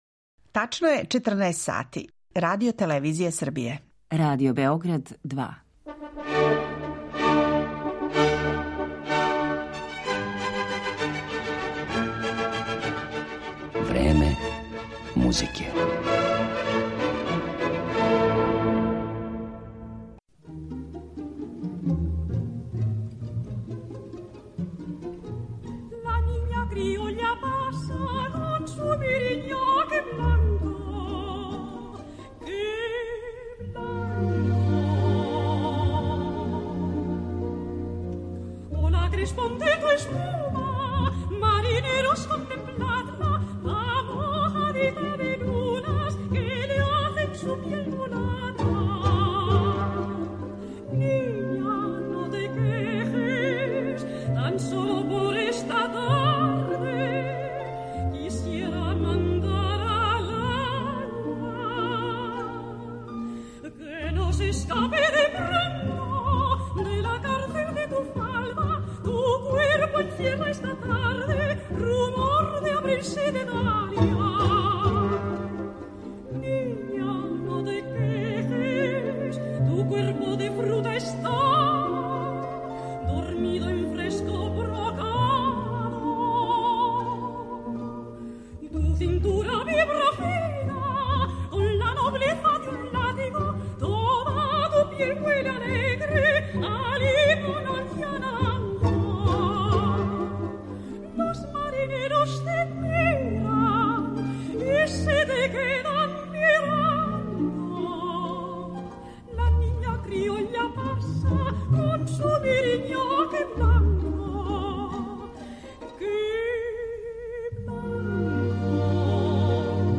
Хабанера